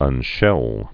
(ŭn-shĕl)